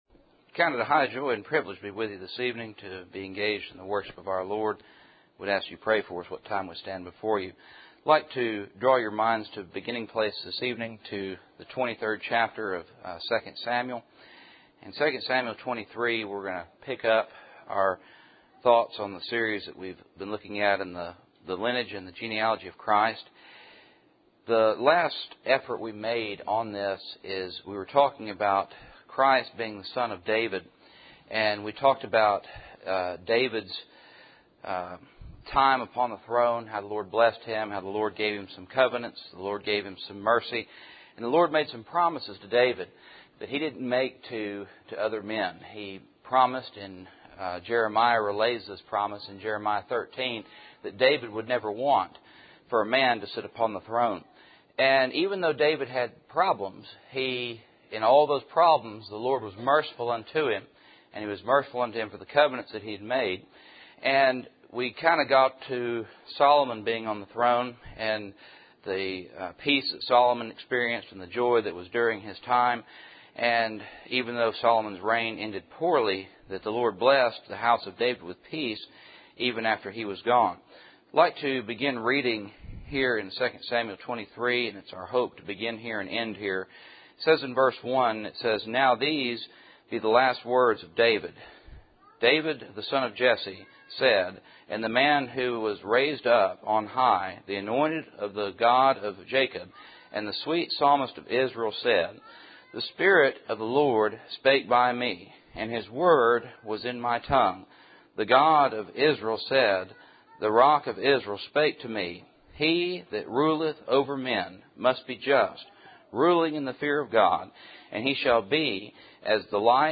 Service Type: Cool Springs PBC Sunday Evening